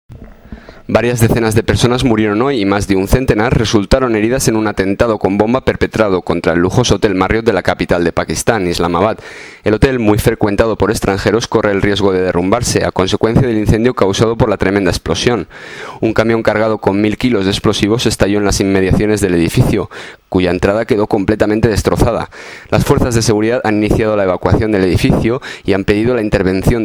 Muestra de las crónicas de radio hechas con la Agencia EFE en el sur de Asia entre 2008 y 2013.